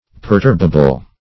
Meaning of perturbable. perturbable synonyms, pronunciation, spelling and more from Free Dictionary.
Search Result for " perturbable" : The Collaborative International Dictionary of English v.0.48: Perturbable \Per*turb"a*ble\, a. Liable to be perturbed or agitated; liable to be disturbed or disquieted.